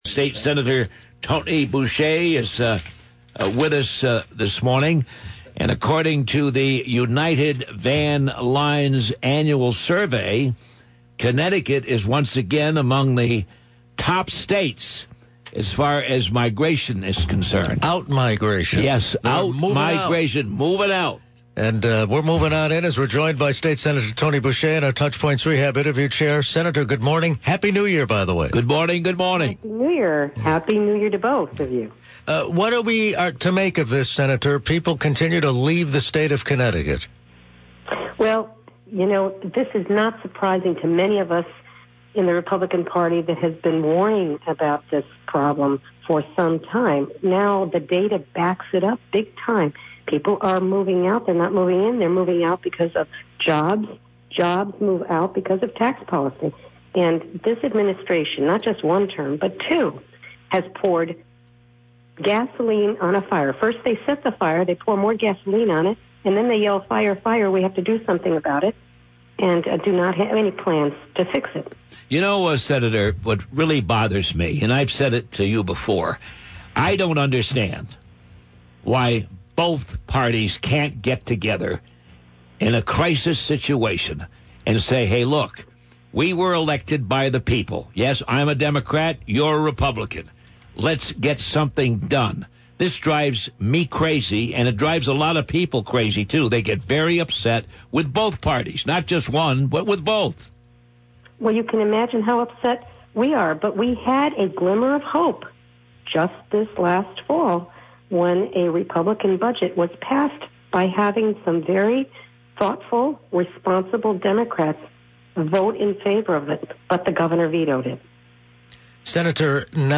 The senator made her comments during an interview about a new report, showing Connecticut is among the nation's top four states in out migration.